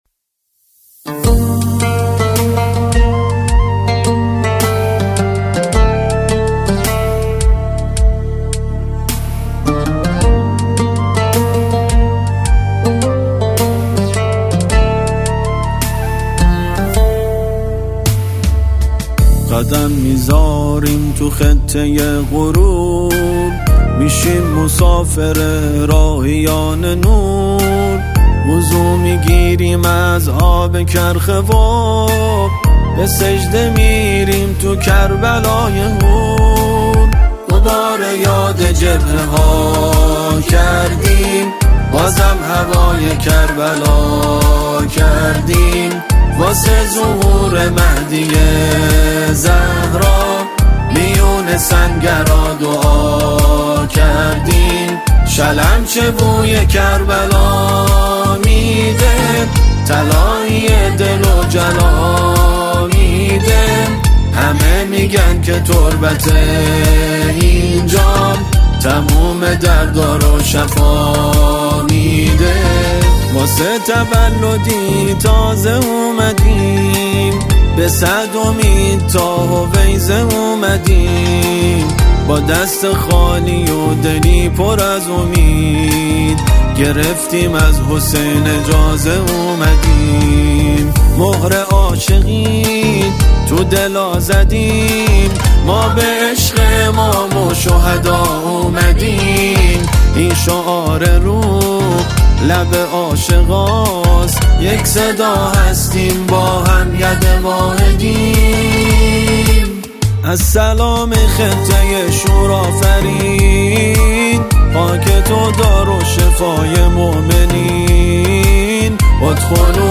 یک اثر پرشور و حماسی
تک‌خوانی